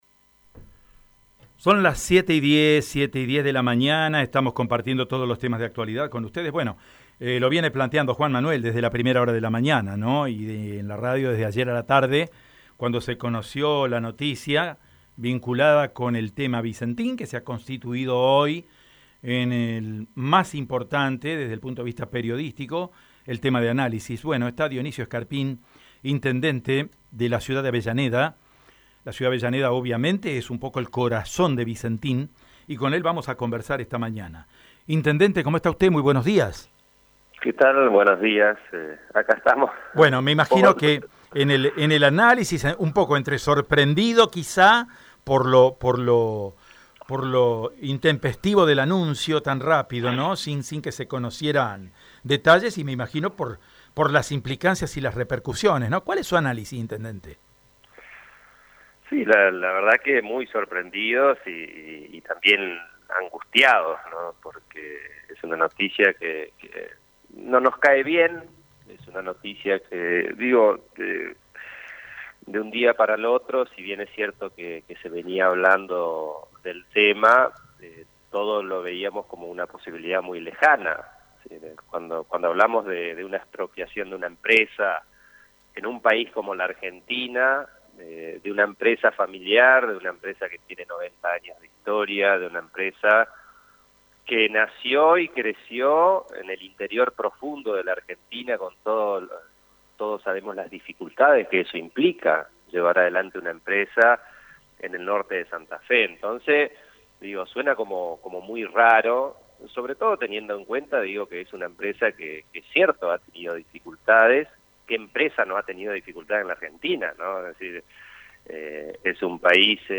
Dionisio Scarpín (Frente Progresista), intendente de Avellaneda, sostuvo en Estilo EME (Lunes a Viernes de 6.30 a 9) que la decisión presidencial de estatizar la empresa insignia del norte santafesino tomó de sorpresa a todos.
Dionisio Scarpín en Radio EME:
NOTA-Dionisio-scarpin.-Intendente-de-Avellaneda-Por-tema-Vicentin.mp3